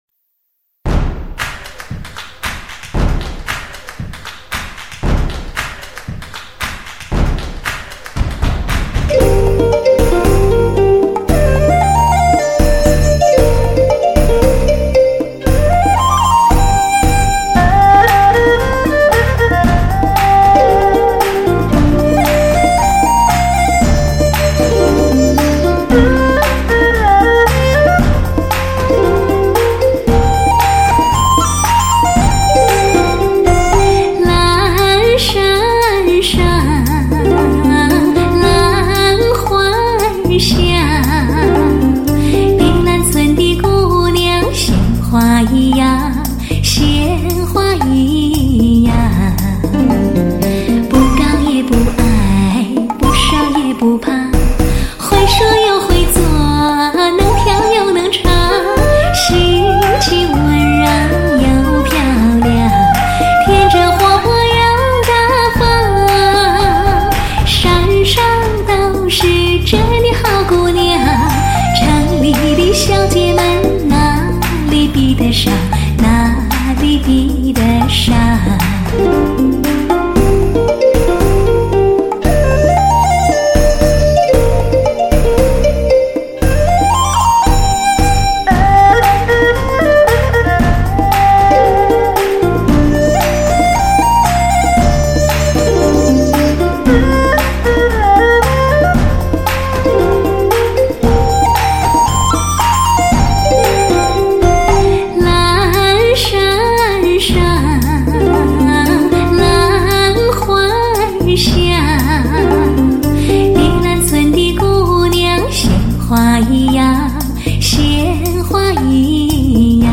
专辑格式：DTS-CD-5.1声道
温文儒雅 款款情心